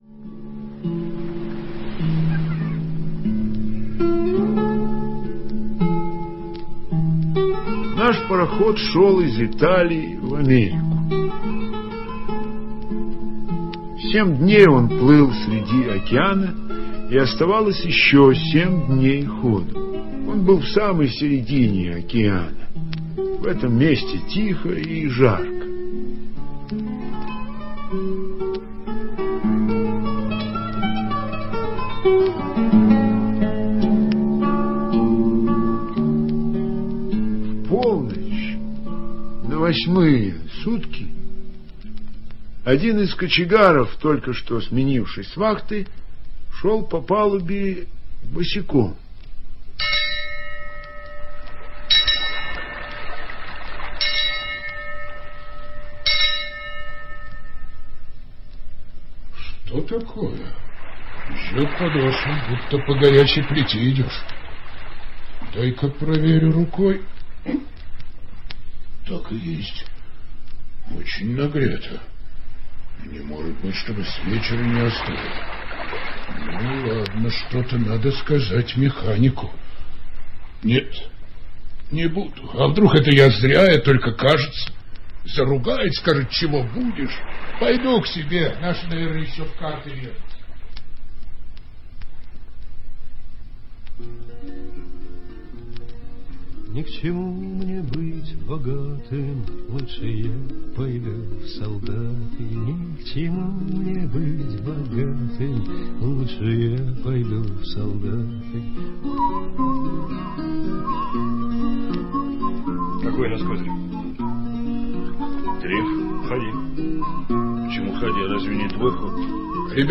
Механик Салерно - аудио рассказ Житкова Б.С. История произошла на итальянском судне.